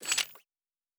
pgs/Assets/Audio/Sci-Fi Sounds/Weapons/Weapon 08 Foley 2 (Laser).wav at master
Weapon 08 Foley 2 (Laser).wav